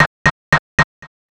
(Fx) The Ends Snare Triplets(1).wav